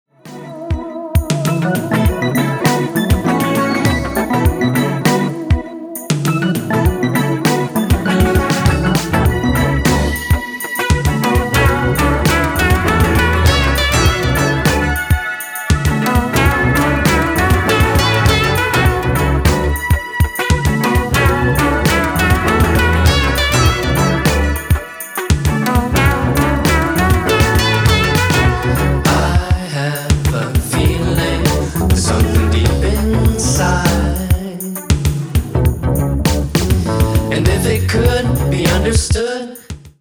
全編に渡って脱力感の漂うベッドルーム・ポップ/シンセ・ファンク/ダウンテンポetcを展開しています。